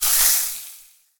cooking_sizzle_burn_fry_04.wav